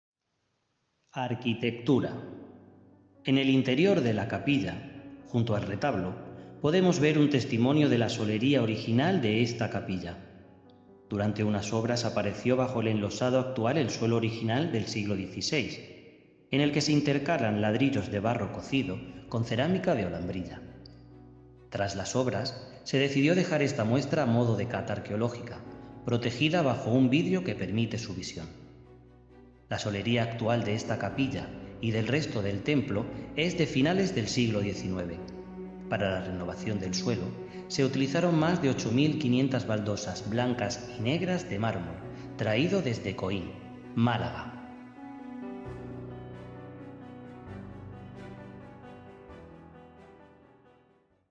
Bloque Principal de la Entidad Ayuntamiento de Segura de León .arquitectura4 Arquitectura / Architecture usted está en Capilla del Sagrario / Tabernacle Chapel » Arquitectura / Architecture Para mejor uso y disfrute colóquese los auriculares y prueba esta experiencia de sonido envolvente con tecnología 8D.